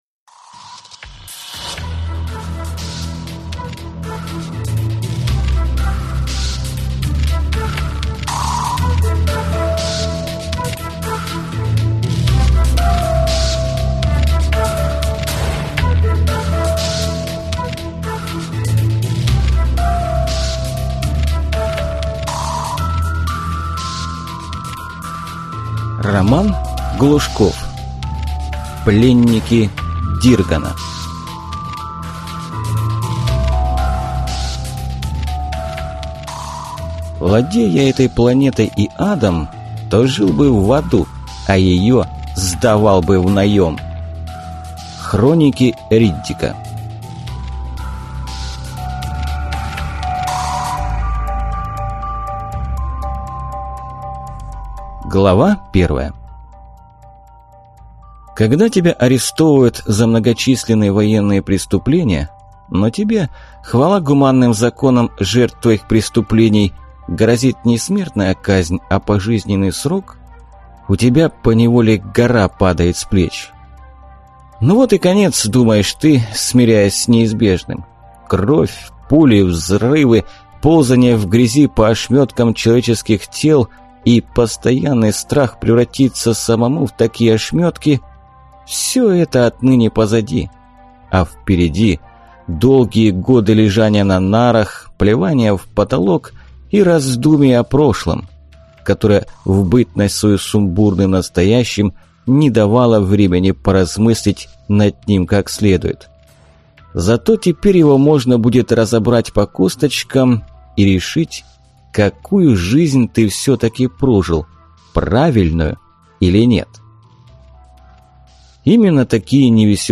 Аудиокнига Пленники Диргана | Библиотека аудиокниг